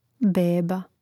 béba beba